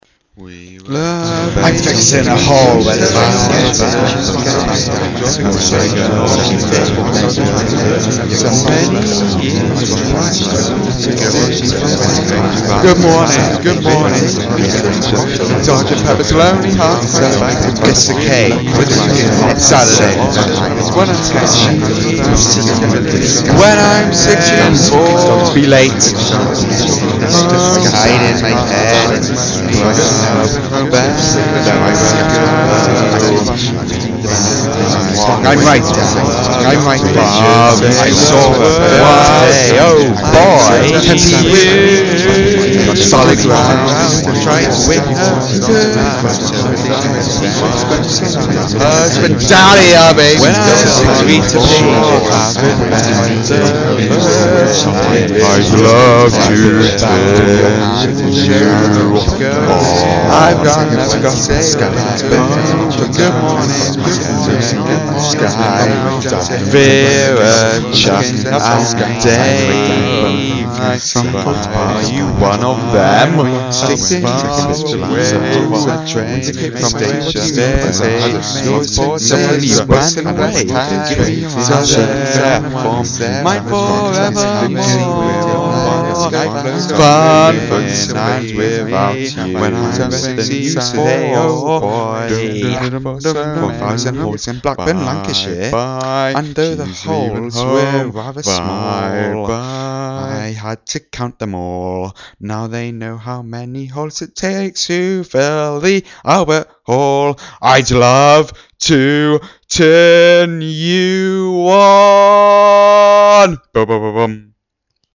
merged together in a far shorter time